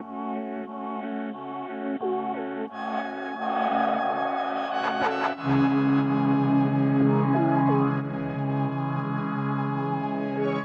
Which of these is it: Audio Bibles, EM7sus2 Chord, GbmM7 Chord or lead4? lead4